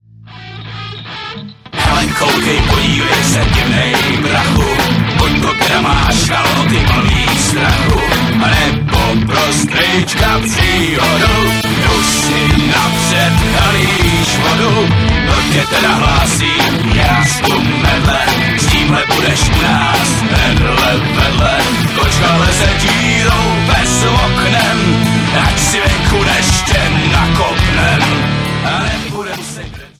v originálním podání dnešních tvrdých kapel